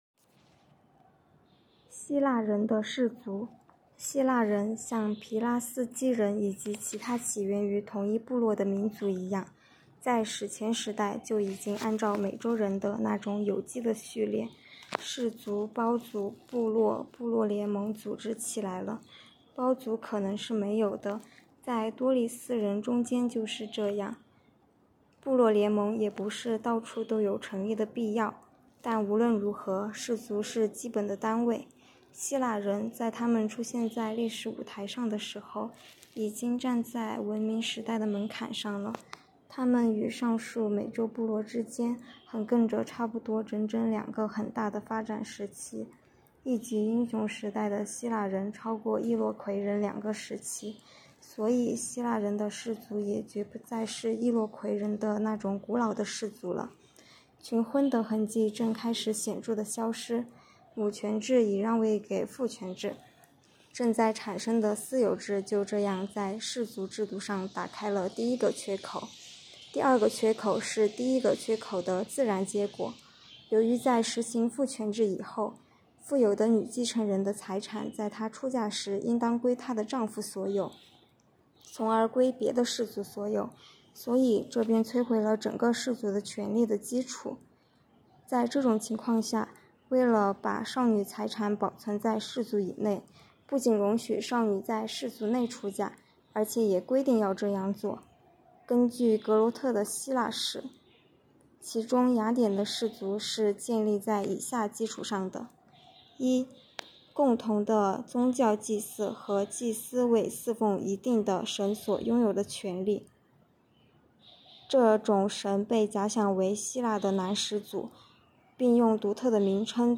“读经典、悟原理”——2025年西华大学马克思主义经典著作研读会接力诵读（03期）